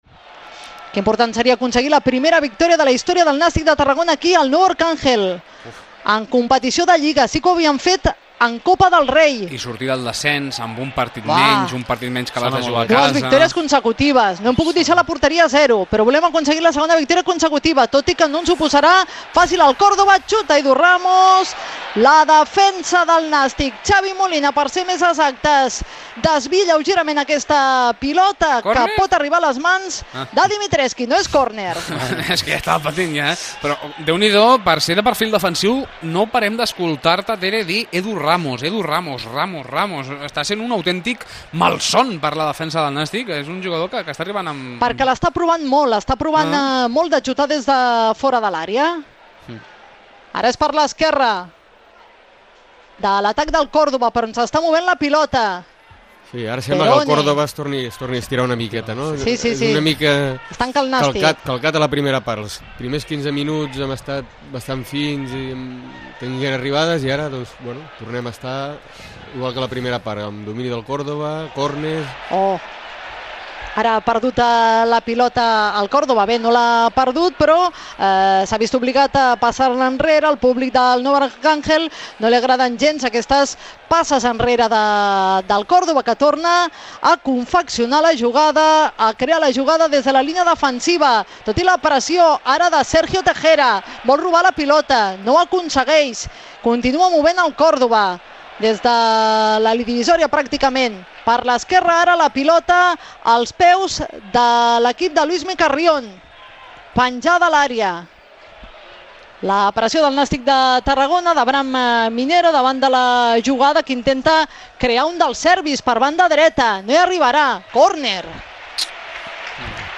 Transmissió partit de futbol Córdova-Nàstic - Tarragona Ràdio